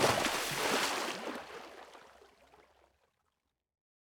small-splash-2.ogg